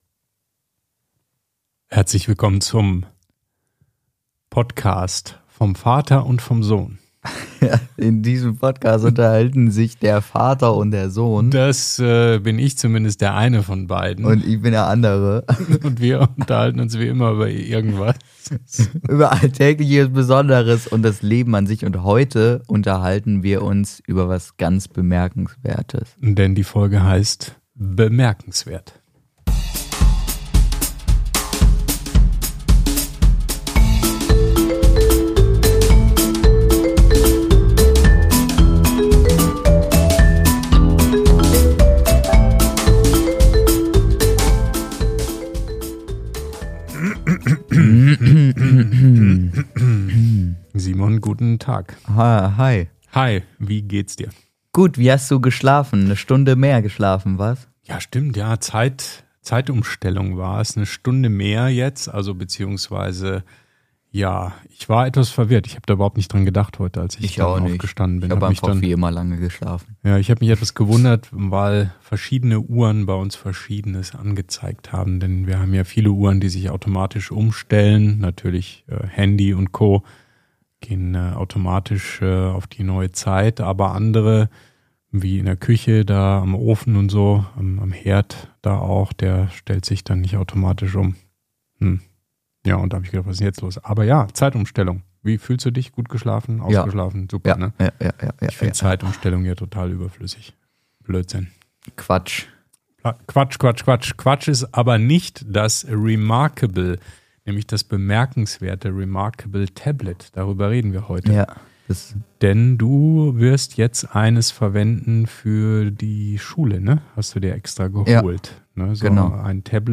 Vater Sohn Podcast Vater und Sohn. Reden.
Natürlich gibt’s auch wieder ehrliche Hörerfragen, ein bisschen Musik und unseren ganz persönlichen Blick auf die kleinen und großen Dinge, die uns wirklich bemerkenswert erscheinen.